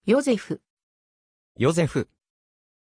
Pronunciation of Joseph
pronunciation-joseph-ja.mp3